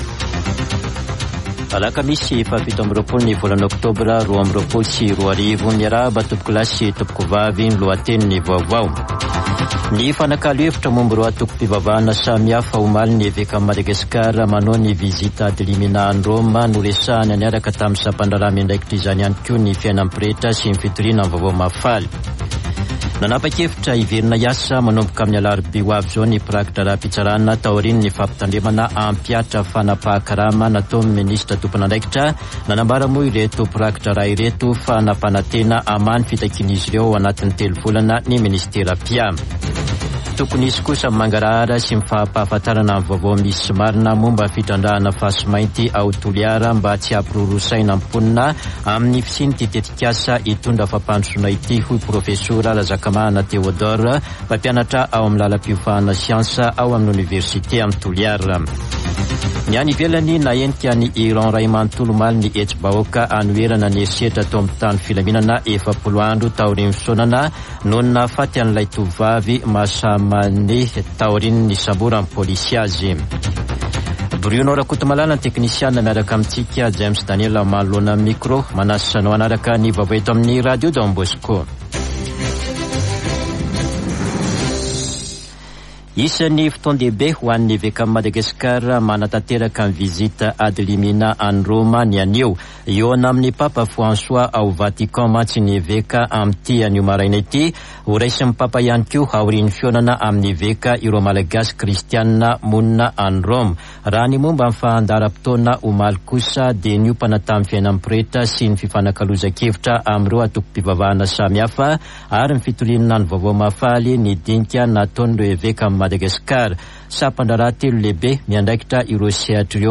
[Vaovao maraina] Alakamisy 27 ôktôbra 2022